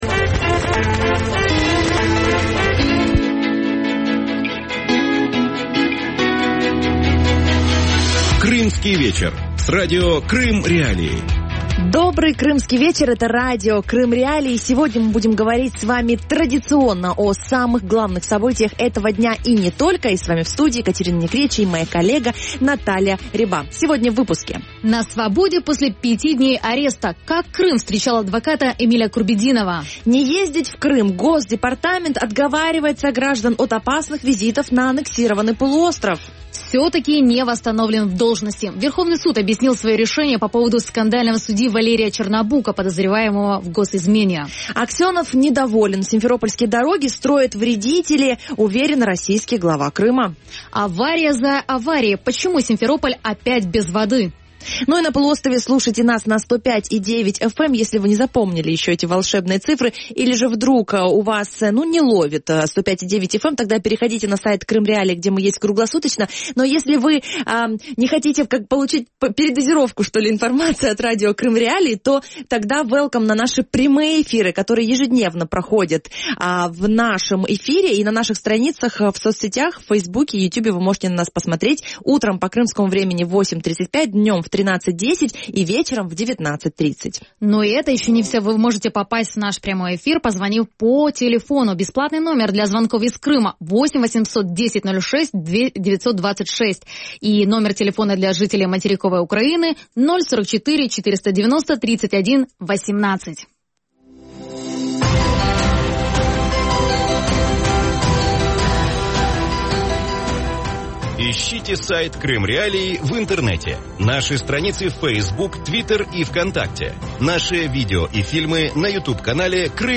И почему его лишили статуса адвоката в России? Интервью с Марком Фейгиным. Крымский адвокат Эмиль Курбединов сегодня вышел из Симферопольского СИЗО.
Гость эфира: российский юрист Марк Фейгин